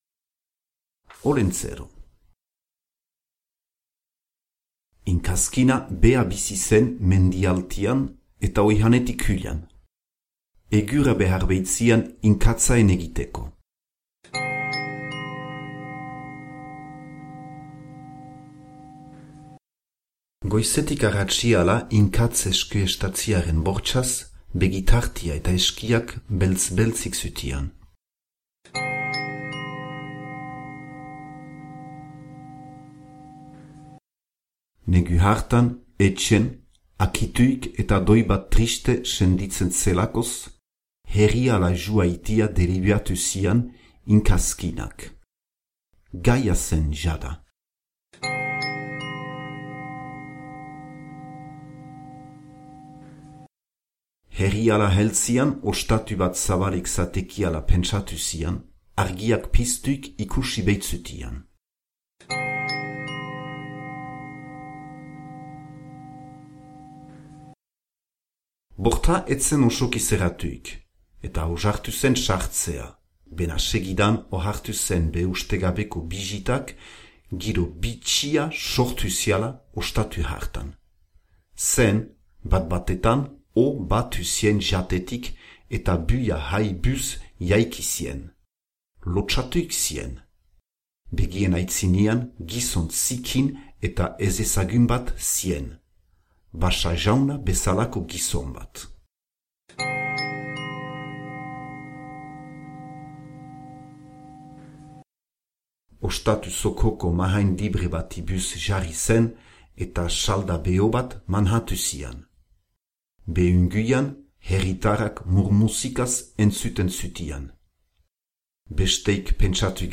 Olentzero - ipuina entzungai - zubereraz